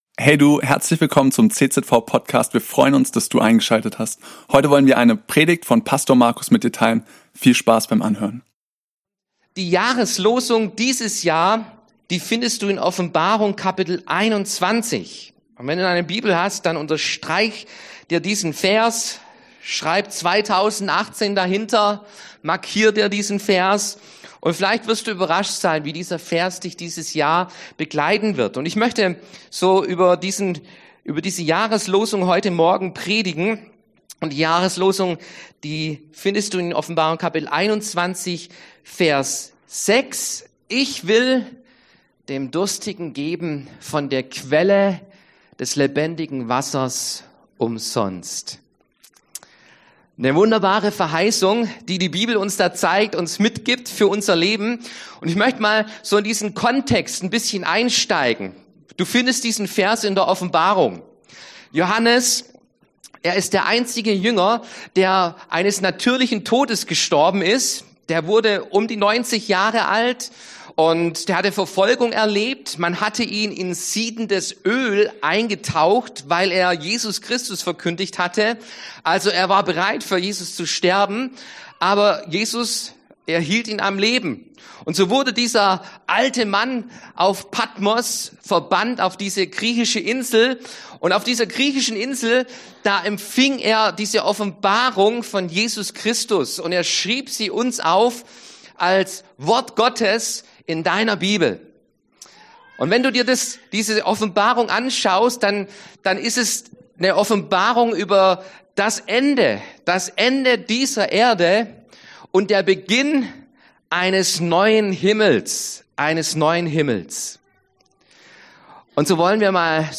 Dieser Bibelvers aus Offenbarung 21,6 ist die Jahreslosung des Jahres 2018 und auch das Thema unserer ersten Predigt im neuen Jahr.